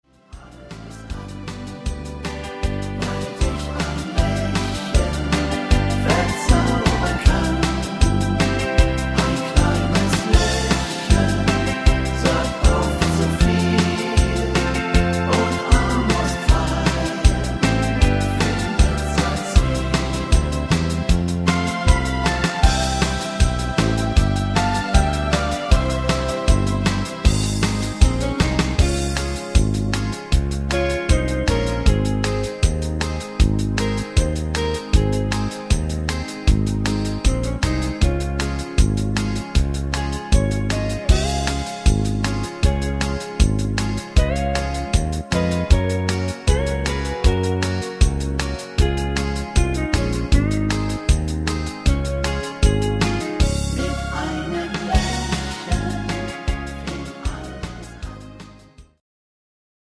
Rhythmus  Fox Shuffle
Art  Deutsch, Oldies, Tanzschule